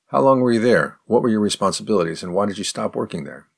Spoken fast:
04_advanced_question_fast.mp3